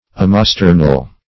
Omosternal \O`mo*ster"nal\